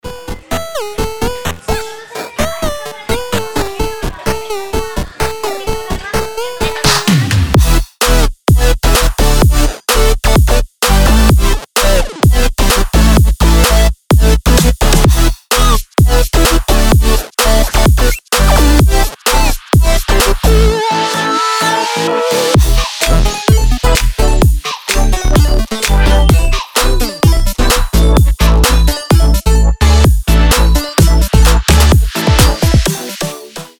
веселые
саундтреки
Electronica
8-бит
Breaks
breakbeat